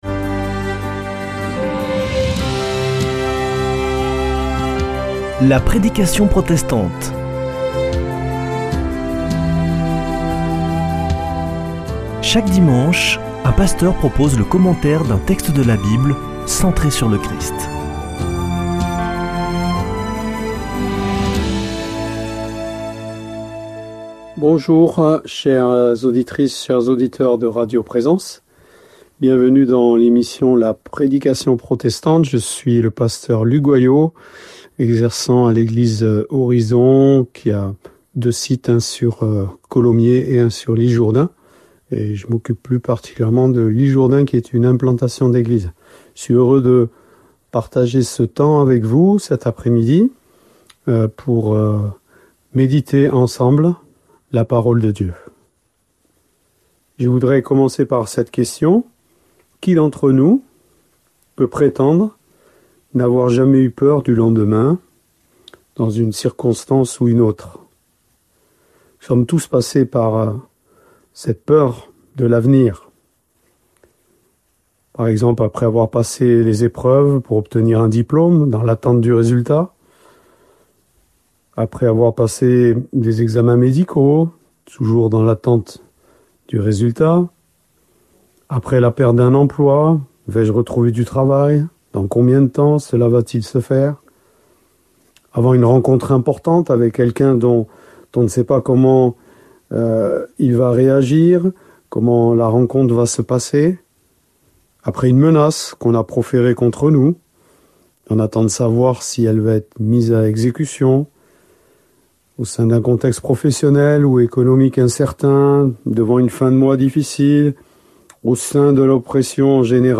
Accueil \ Emissions \ Foi \ Formation \ La prédication protestante \ As-tu peur de l’avenir ?